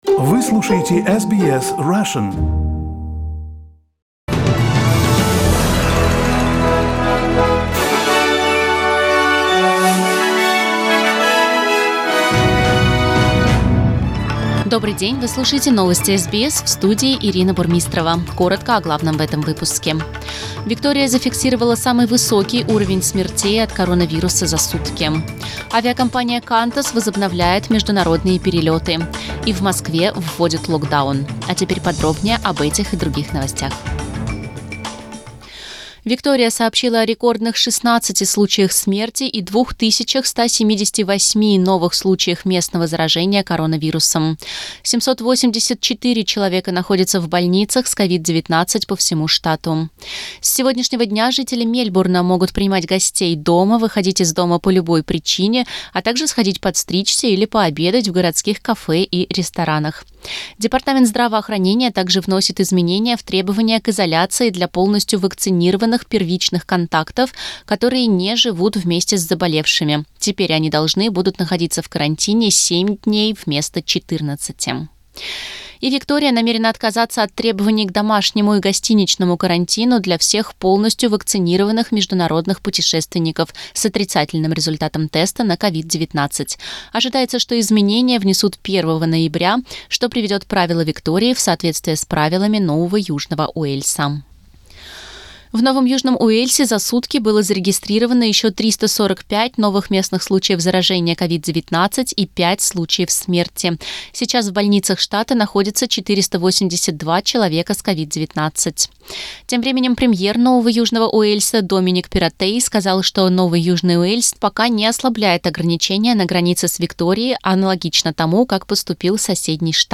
Новости SBS на русском языке - 22.10